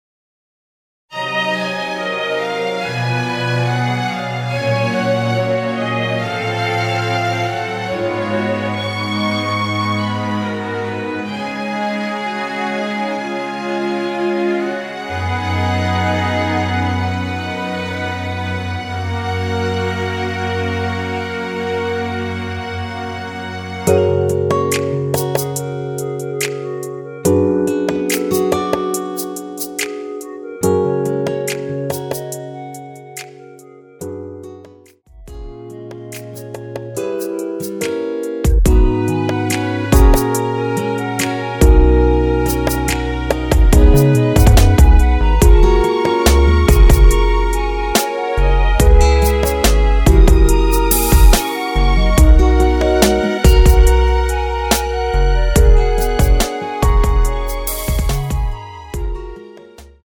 원키에서(+2)올린 멜로디 포함된 MR입니다.
주 멜로디만 제작되어 있으며 화음 라인 멜로디는 포함되어 있지 않습니다.(미리듣기 참조)
F#
앞부분30초, 뒷부분30초씩 편집해서 올려 드리고 있습니다.
중간에 음이 끈어지고 다시 나오는 이유는